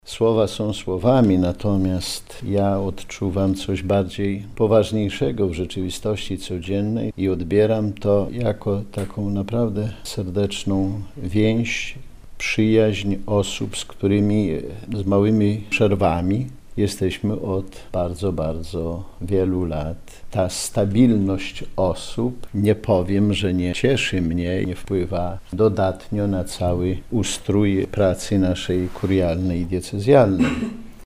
Biskup Romuald Kamiński, ordynariusz warszawsko-praski